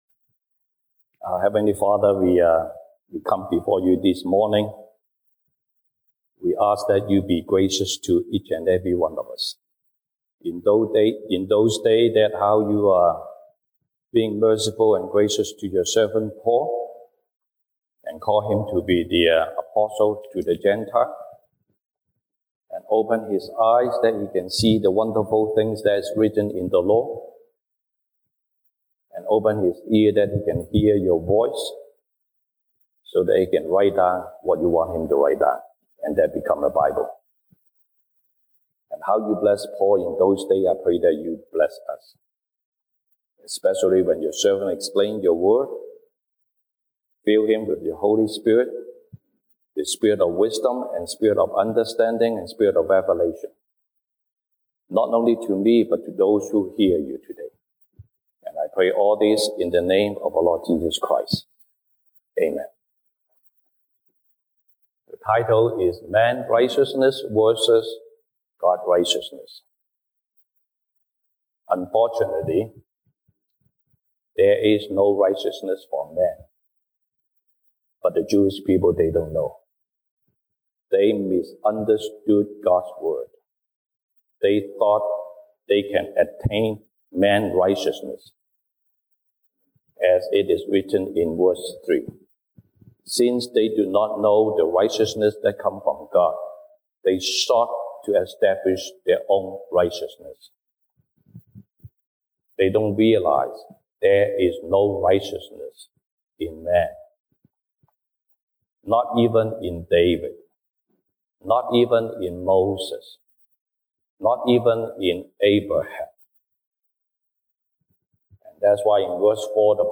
Service Type: 西堂證道 (英語) Sunday Service English Topics: Man's righteousness versus God's righteousness